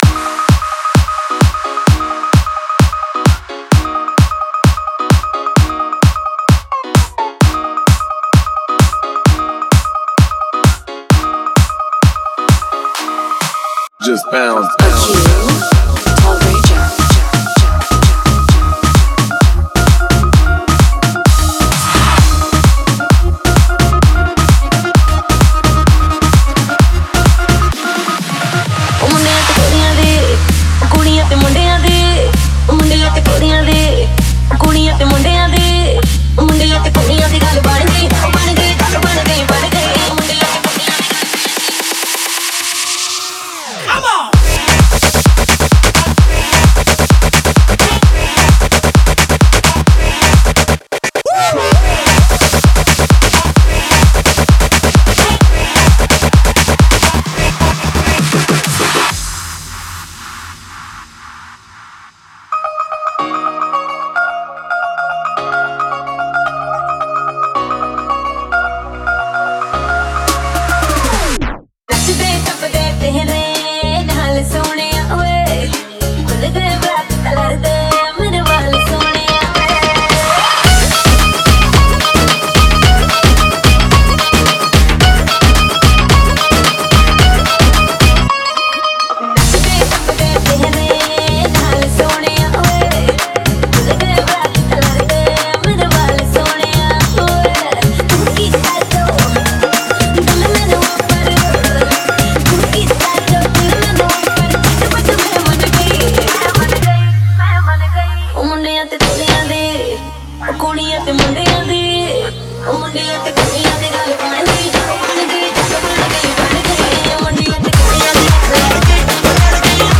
Bollywood DJ Remix Songs